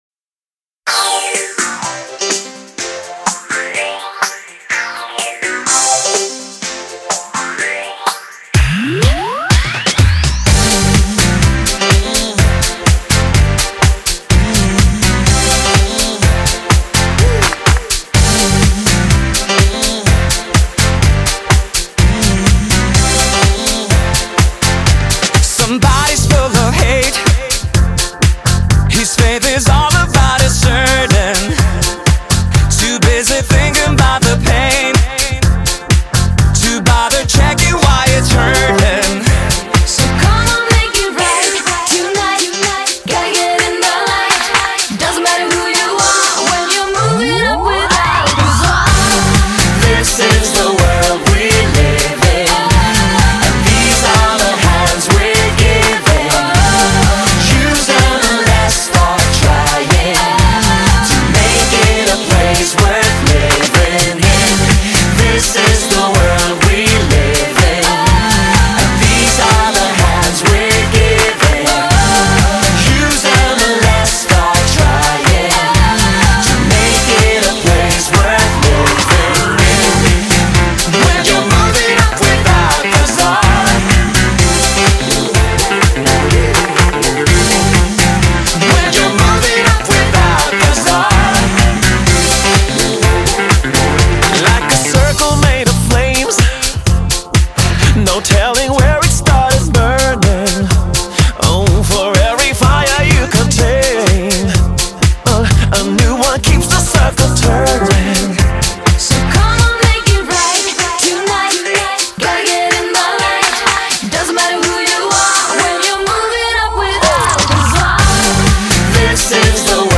Жанр: Eurodаnce Страна: Sweden